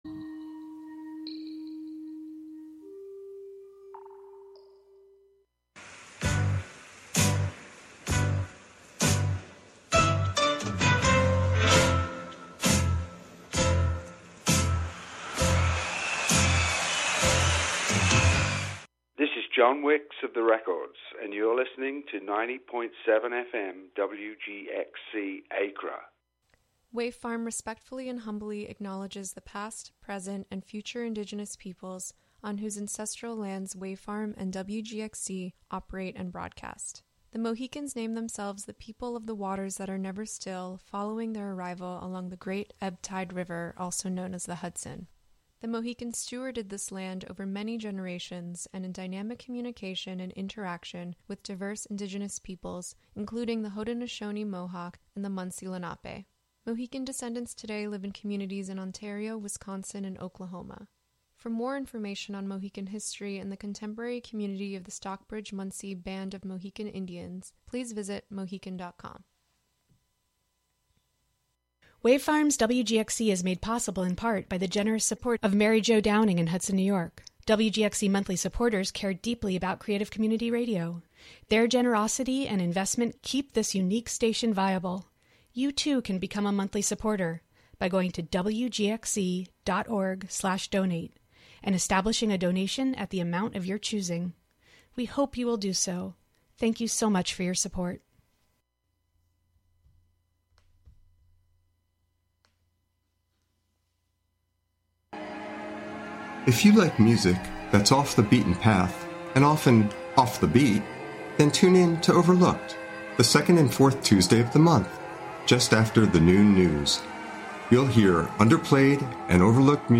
Live from the Catskill Makers space on W. Bridge St. in Catskill, a monthly show about science, technology, fixing, making, hacking, and breaking with the amorphous collection of brains comprising the "Skill Syndicate."